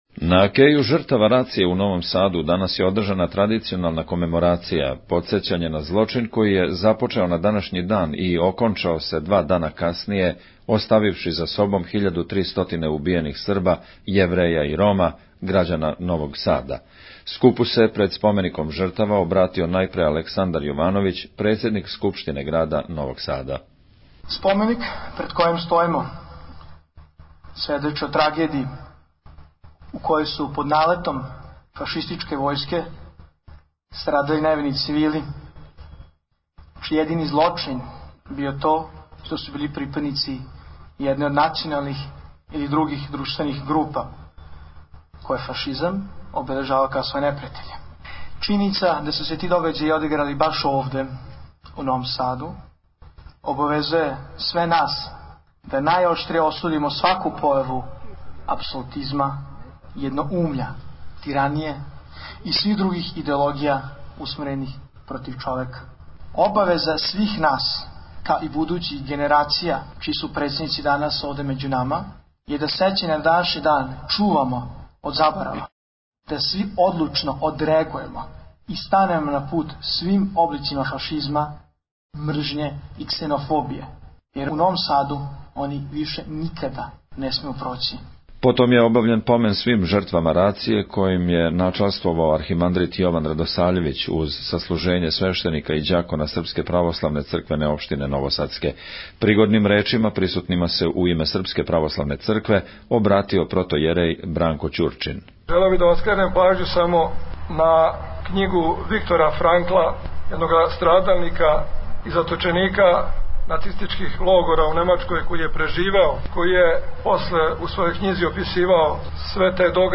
Парастос жртвама новосадске рације
На Кеју жртава рације у Новом Саду данас је одржан молитвени помен житељима нашег града који су невино пострадали у злочину мађарских фашиста јануара 1942. године.
Присутнима се, пред спомеником жртава, обратио Александар Јовановић, председник Скупштине града Новог Сада.